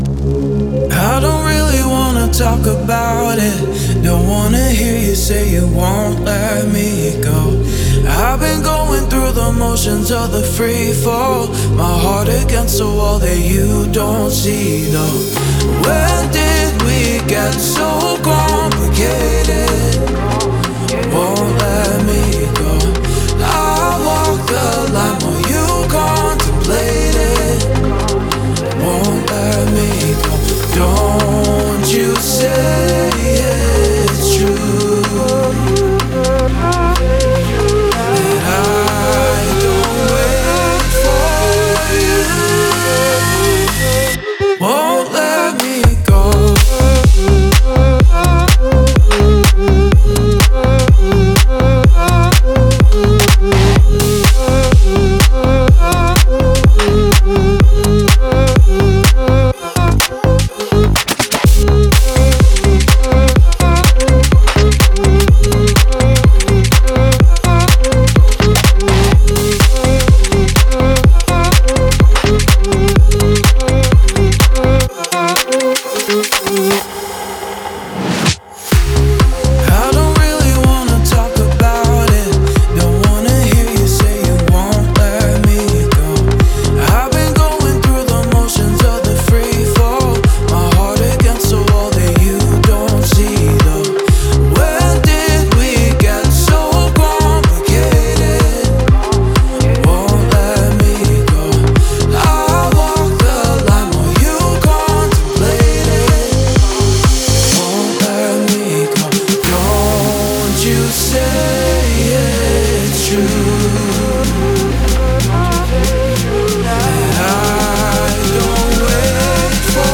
Текст песни Музыка СПОКОЙНАЯ МУЗЫКА